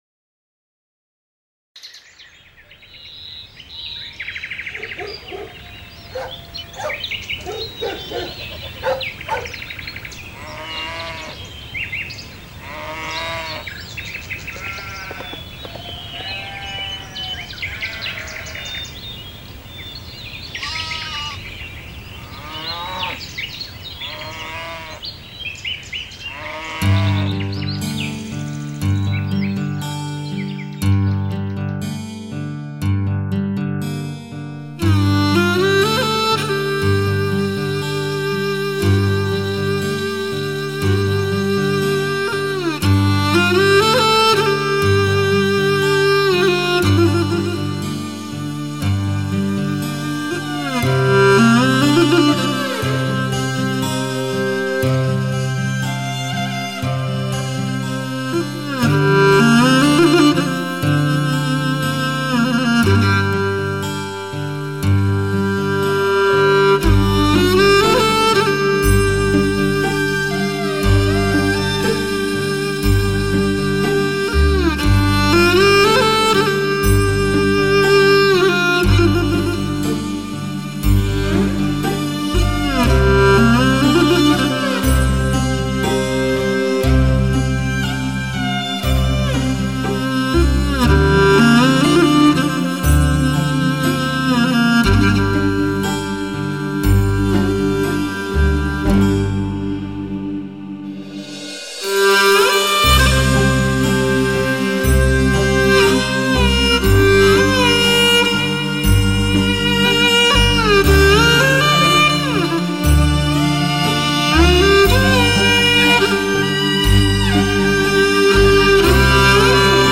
版本: LPCD45黑胶